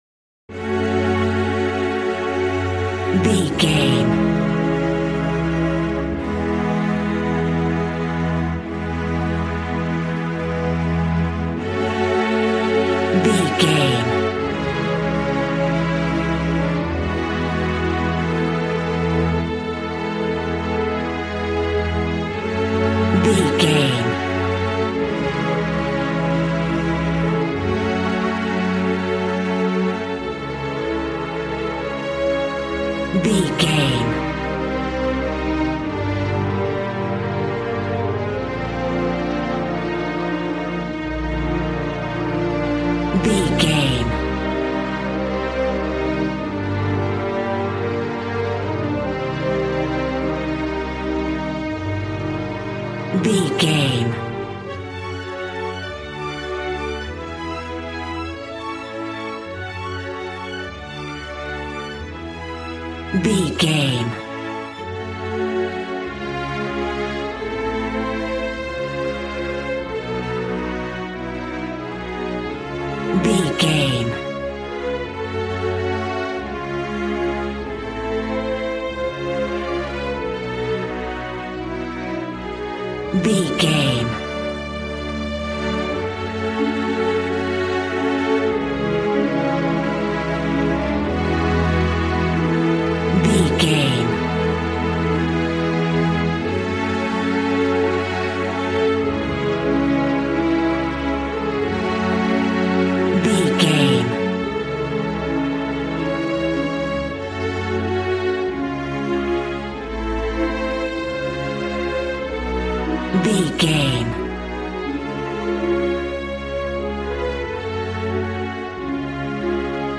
Ionian/Major
regal
cello
violin
brass